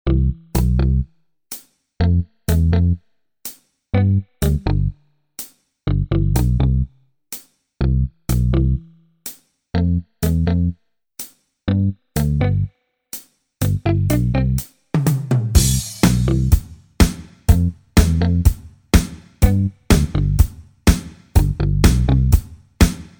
-  Mp3 Mp3 Instrumental Song Track